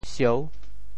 绍（紹） 部首拼音 部首 纟 总笔划 8 部外笔划 5 普通话 shào 潮州发音 潮州 sieu6 文 潮阳 siao6 澄海 siou6 揭阳 siao6 饶平 siao6 汕头 siao6 中文解释 潮州 sieu6 文 对应普通话: shào ①连续，继承：～复（继承恢复） | ～述（继承） | ～世（连续几世）。
sieu6.mp3